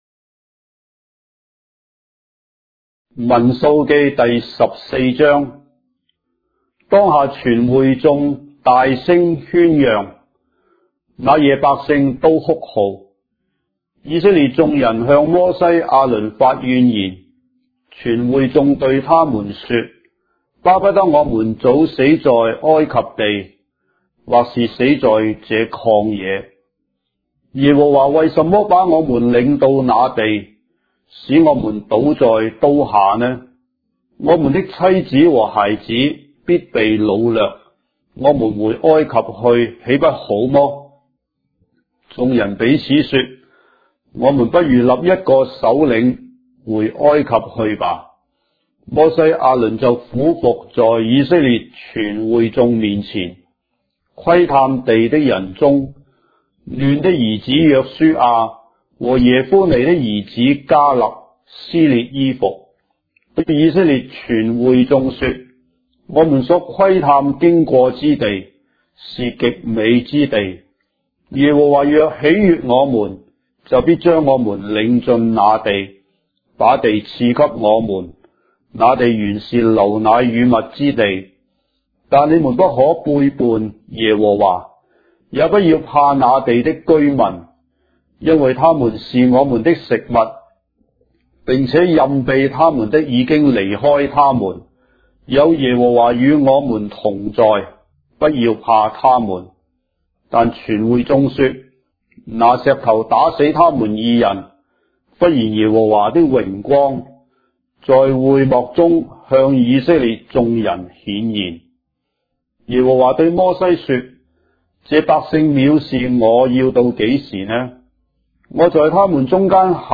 章的聖經在中國的語言，音頻旁白- Numbers, chapter 14 of the Holy Bible in Traditional Chinese